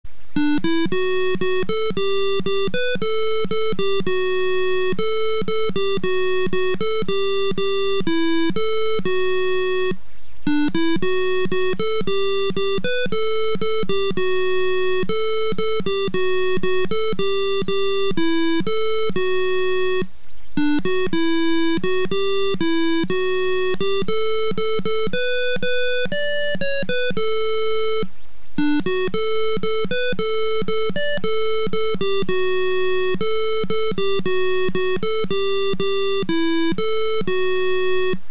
1. Man kann zwischen den zwei Noten-Melodien wählen   oder    einer Melodie und einem Sirenenton.